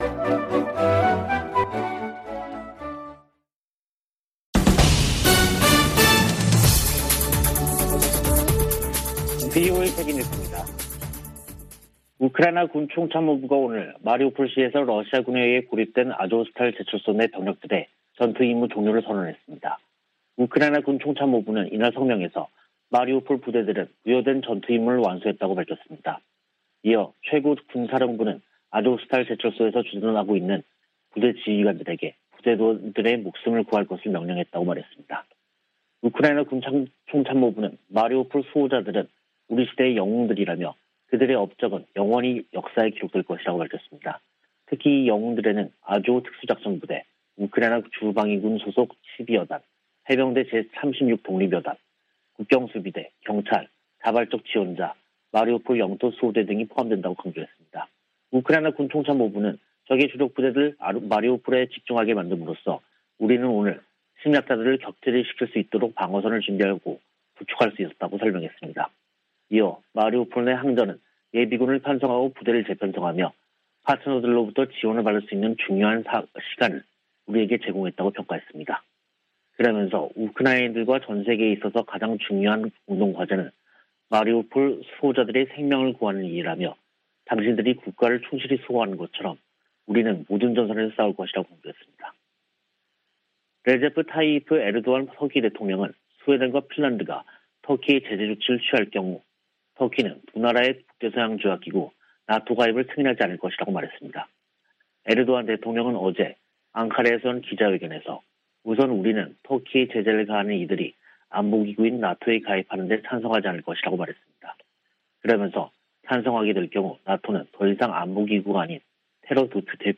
VOA 한국어 간판 뉴스 프로그램 '뉴스 투데이', 2022년 5월 17일 3부 방송입니다. 북한은 연일 신종 코로나바이러스 감염증 발열자가 폭증하는 가운데 한국 정부의 방역 지원 제안에 답하지 않고 있습니다. 세계보건기구가 북한 내 급속한 코로나 확산 위험을 경고했습니다. 북한의 IT 기술자들이 신분을 숨긴 채 활동하며 거액의 외화를 벌어들이고 있다고 미국 정부가 지적했습니다.